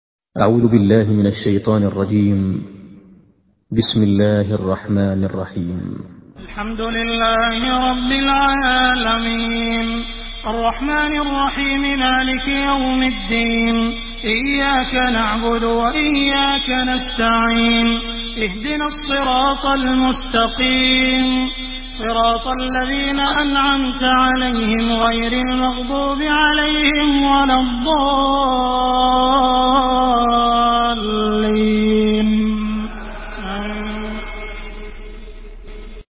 سورة الفاتحة MP3 بصوت عبد الرحمن السديس برواية حفص
مرتل